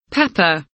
pepper kelimesinin anlamı, resimli anlatımı ve sesli okunuşu